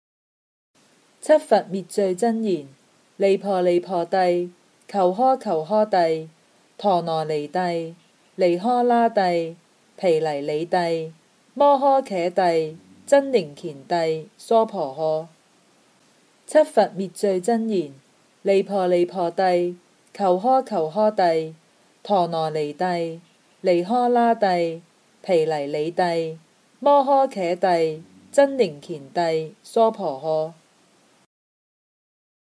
《七佛灭罪真言》经文教念粤语版